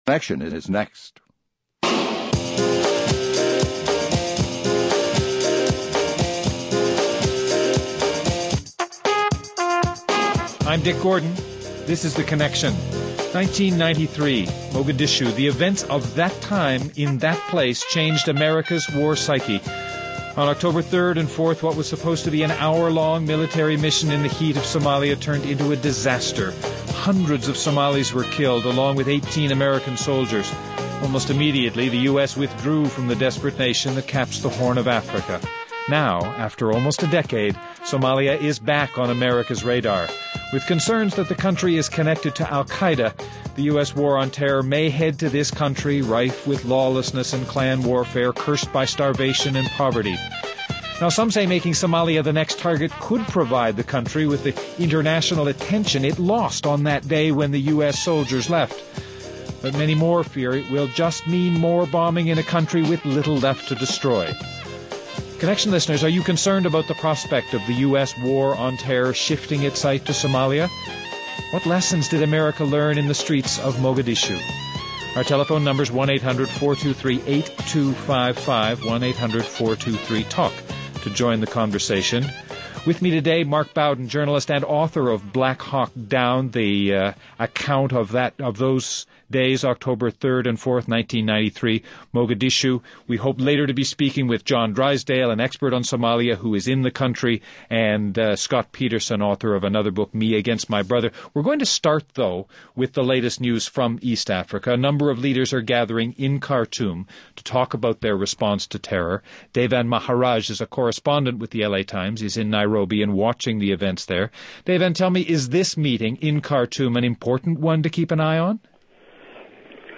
Mark Bowden, author of “Black Hawk Down”